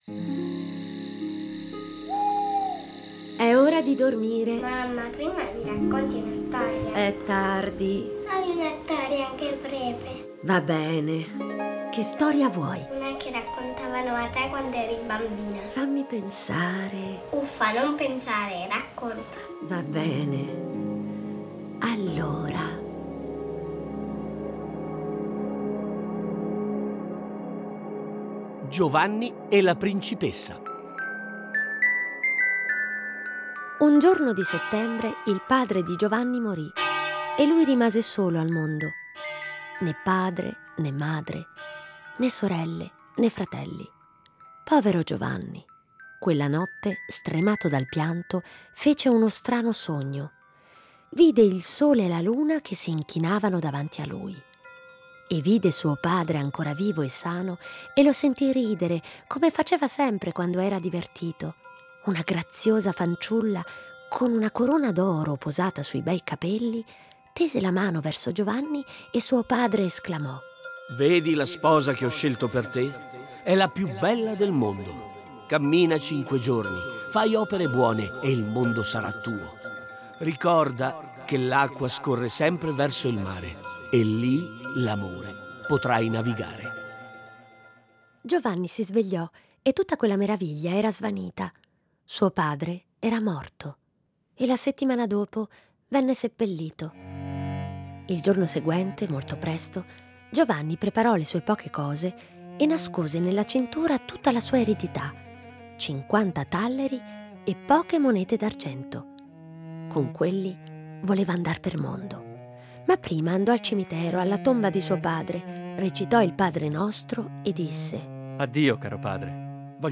A partire dai testi originali un adattamento radiofonico per fare vivere i bambini storie conosciute, ma un po' dimenticate.